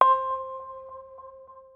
Bell - Gyro.wav